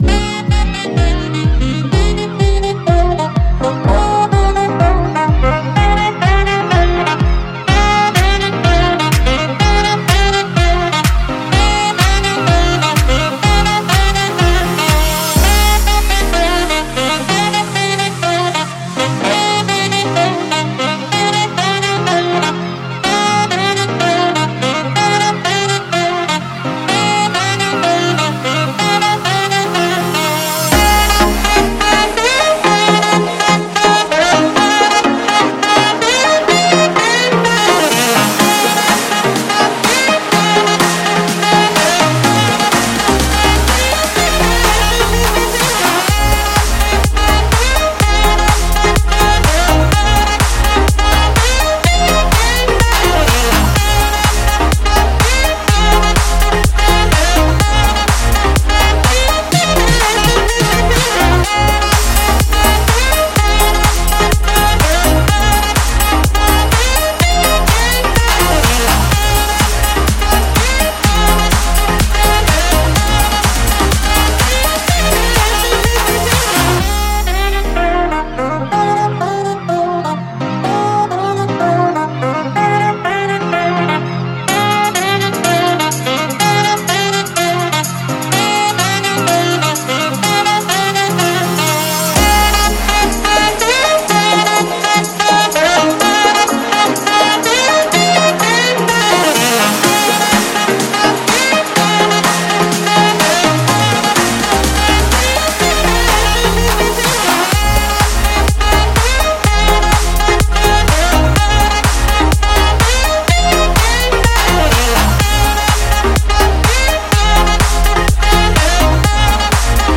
پلی لیست بهترین آهنگ های ساکسوفون (بی کلام)
Saxophone